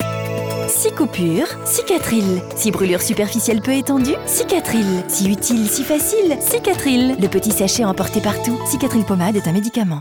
20 - 60 ans - Soprano